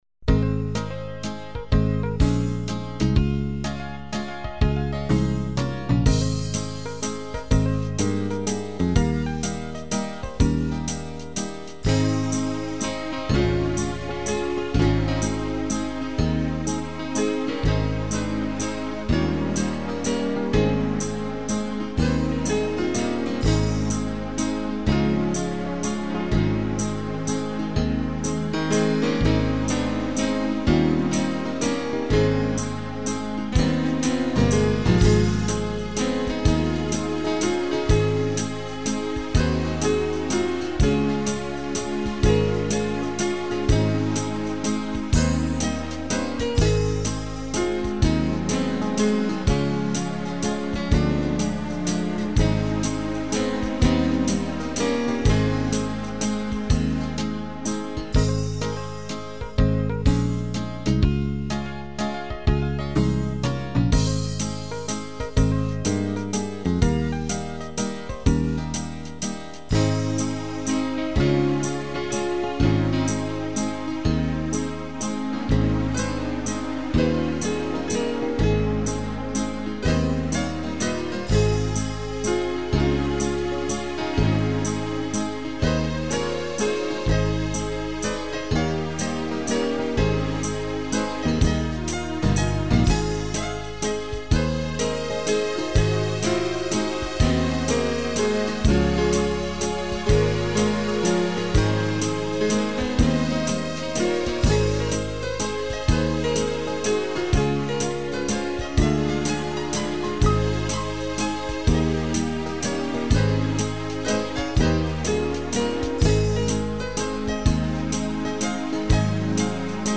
Playbacks-KARAOKE